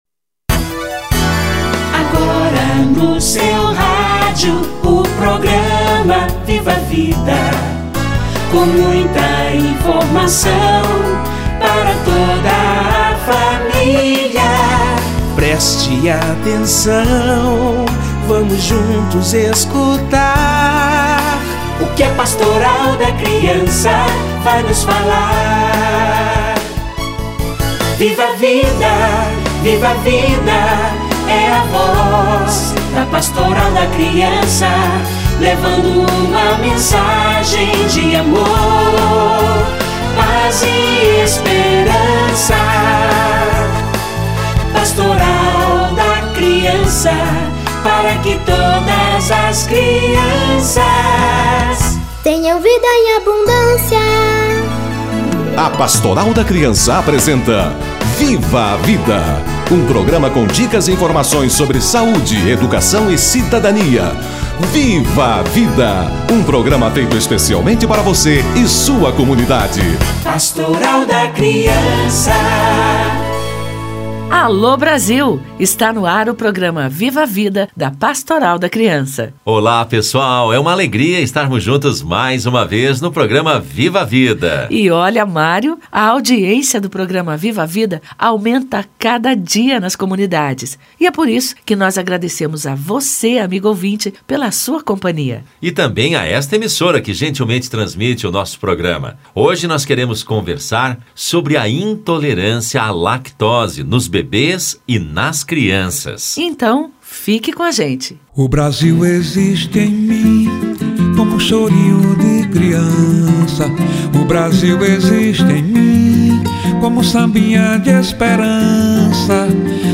Intolerância à lactose - Entrevista